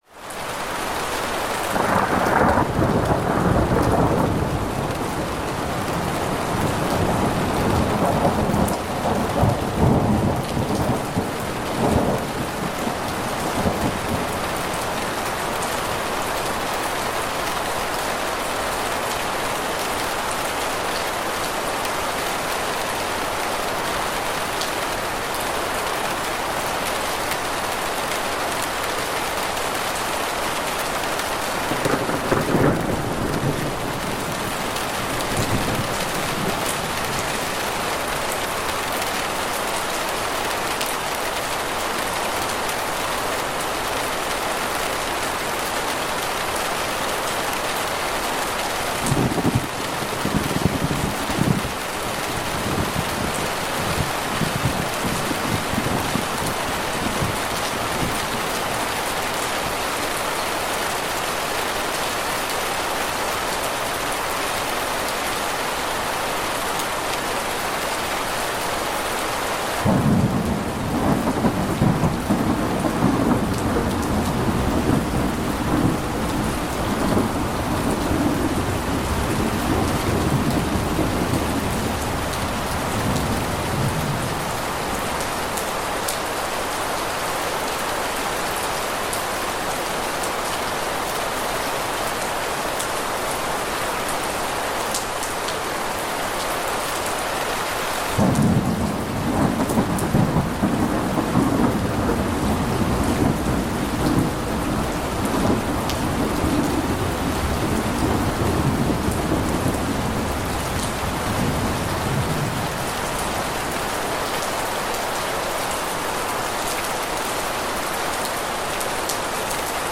Ventana con Lluvia y Trueno para una Noche de Paz